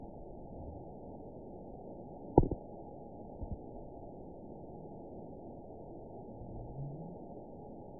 event 920299 date 03/14/24 time 18:53:13 GMT (1 year, 1 month ago) score 9.52 location TSS-AB04 detected by nrw target species NRW annotations +NRW Spectrogram: Frequency (kHz) vs. Time (s) audio not available .wav